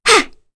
Kirze-Vox_Attack1_kr.wav